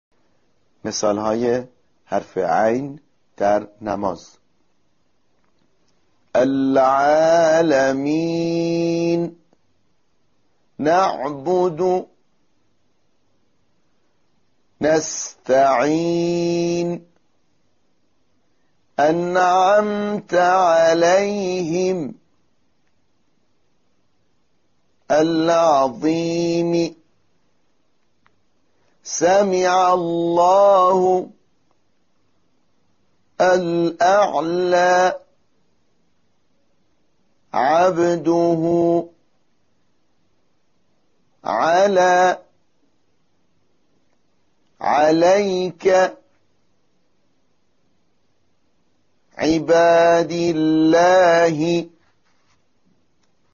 این حرف از حروف حلقی است و مانند حرف «ح» در وسط حلق ساخته می شود.
تمرین عملی_مرحله ۴